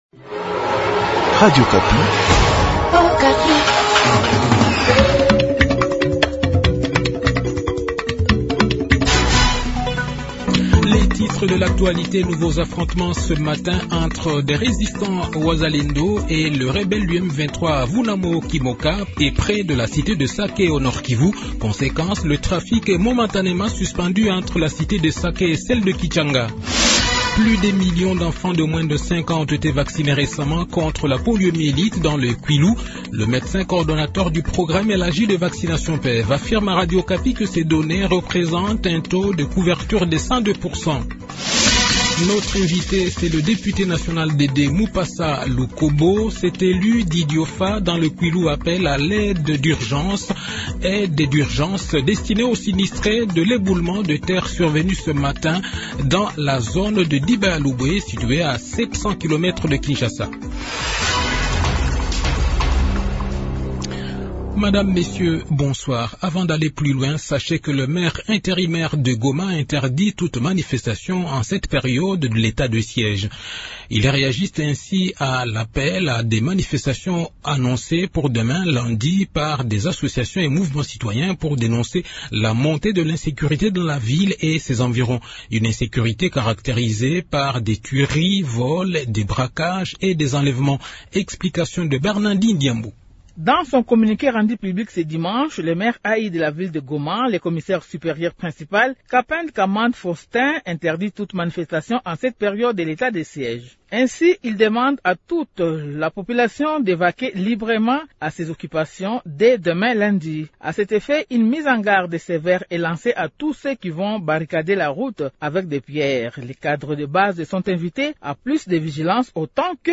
Journal Soir
Le journal de 18 h, 14 avril 2024